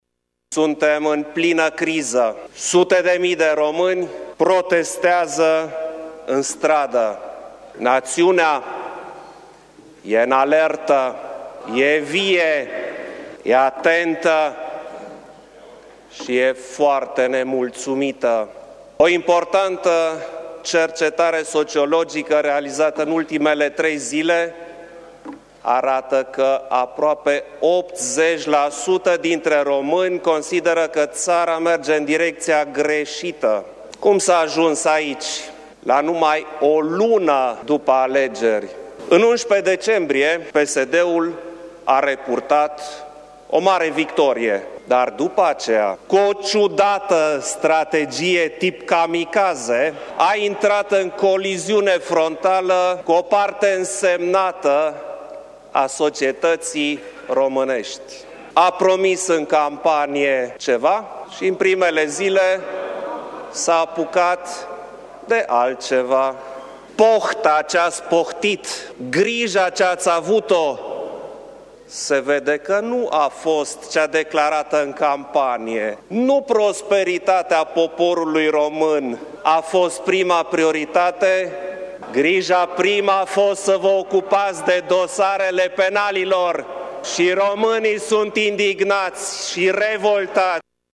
Așa și-a început șeful statului discursul în plenul Parlamentului.